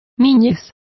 Complete with pronunciation of the translation of infancies.